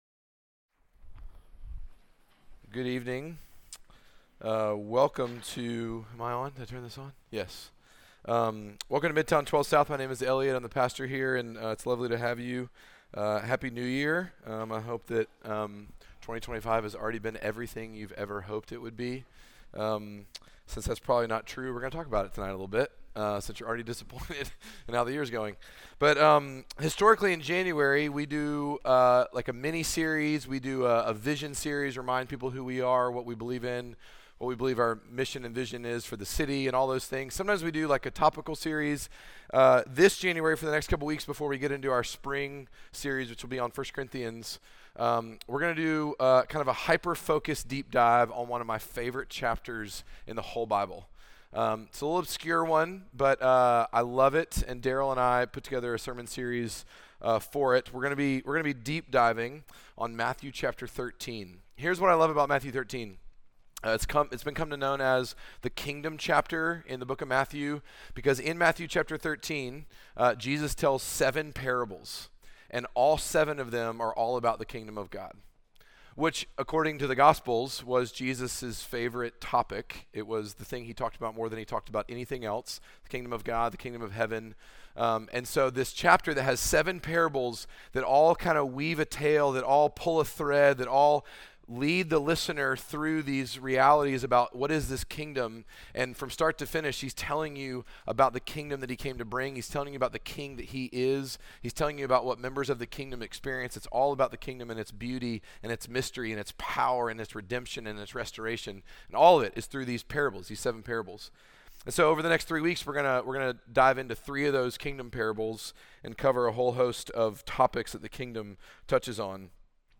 Midtown Fellowship 12 South Sermons Kingdom Sowing Jan 05 2025 | 00:48:17 1x 00:00 / 00:48:17 Subscribe Share Apple Podcasts Spotify Overcast RSS Feed Share Link Embed